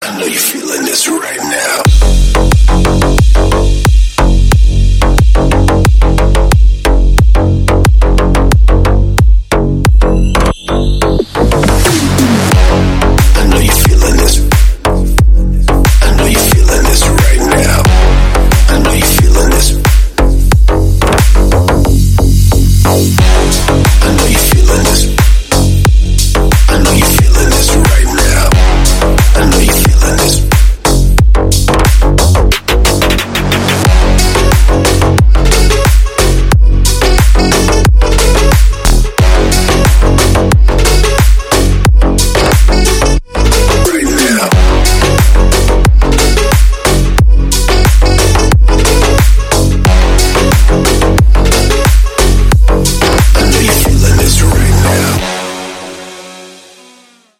Клубные рингтоны
Танцевальные рингтоны , Громкие рингтоны